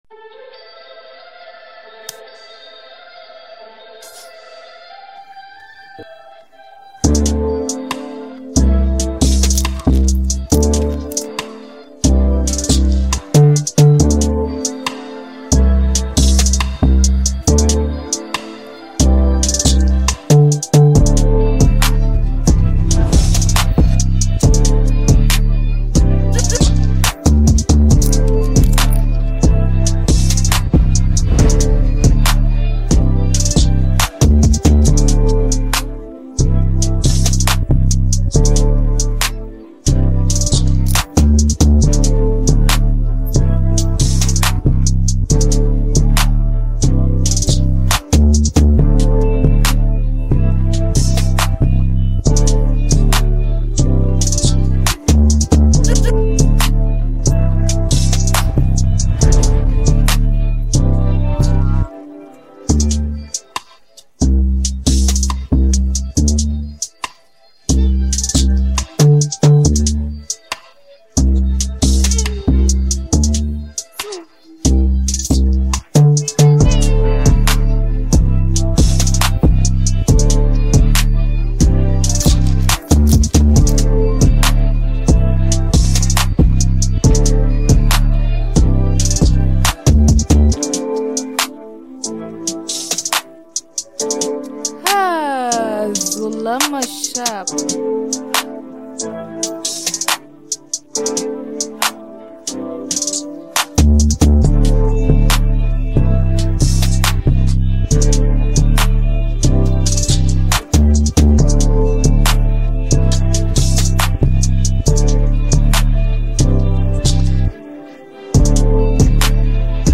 hiphop trap beats
drill